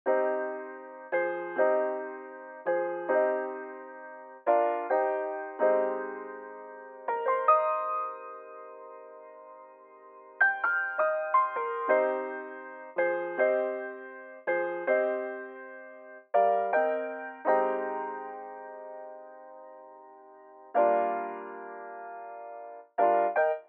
11 piano A.wav